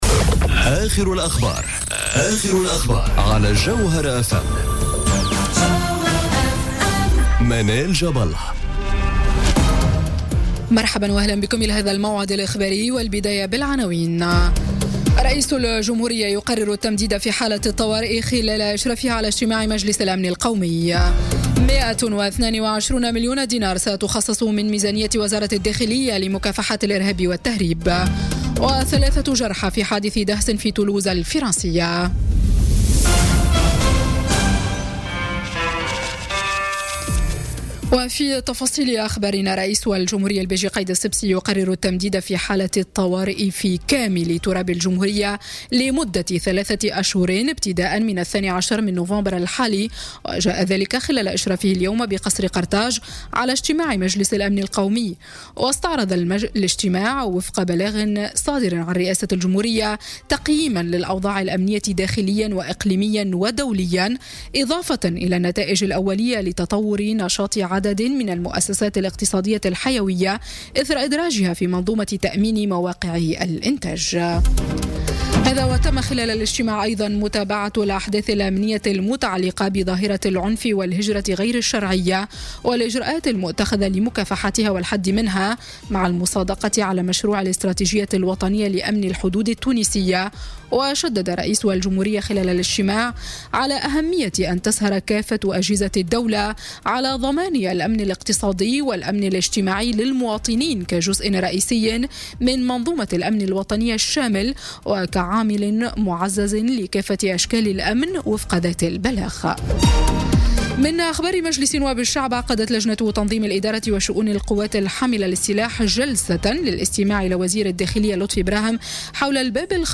نشرة أخبار السابعة مساء ليوم الجمعة 10 نوفمبر 2017